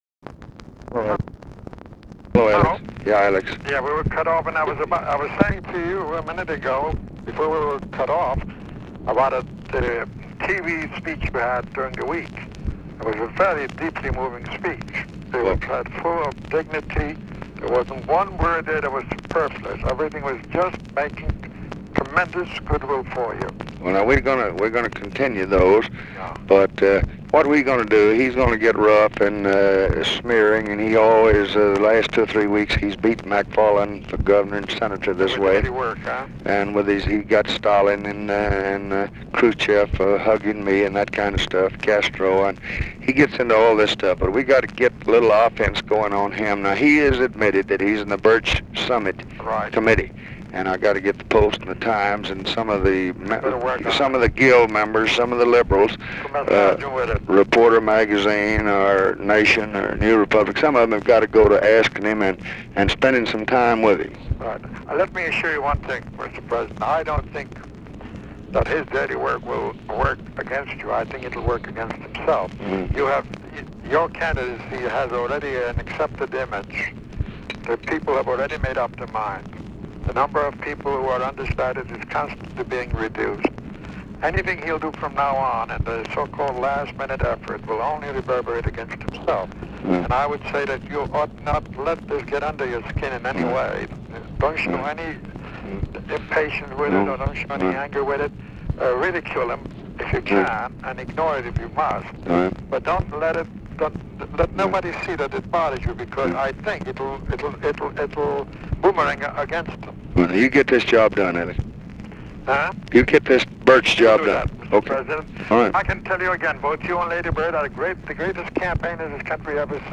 Conversation with ALEX ROSE
Secret White House Tapes